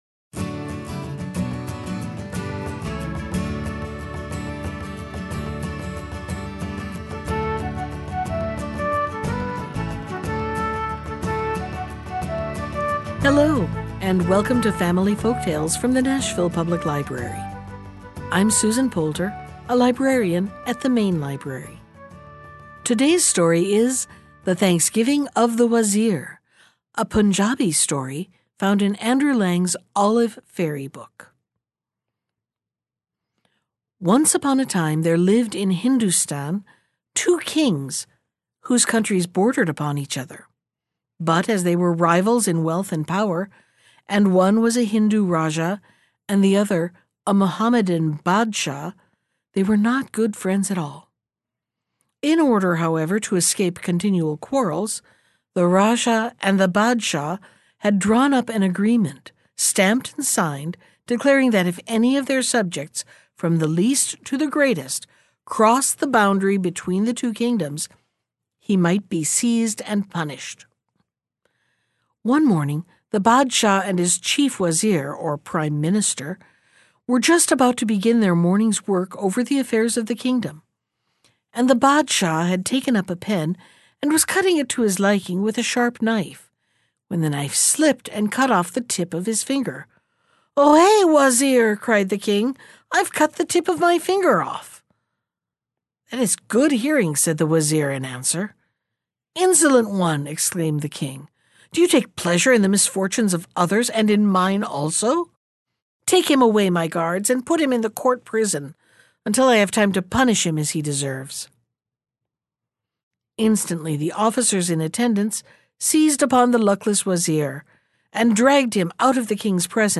Think of it as story time on demand.
Bedtime Stories